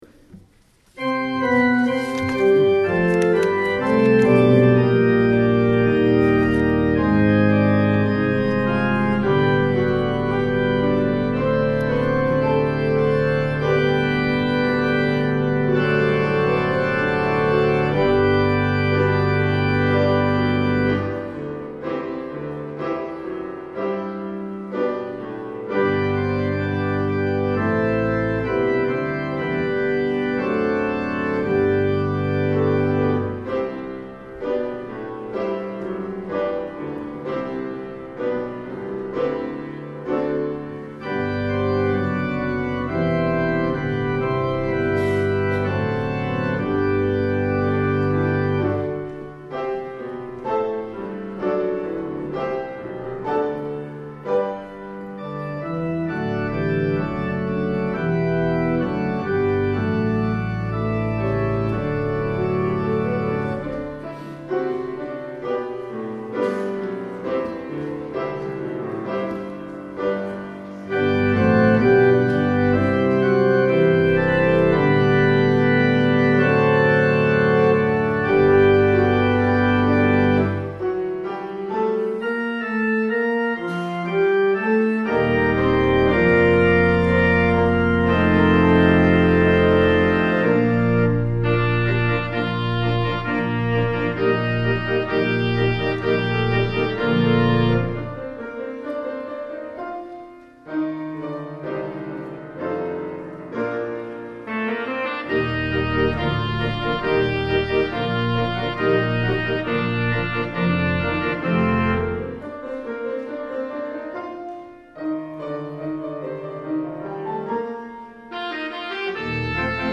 Easter Service 2017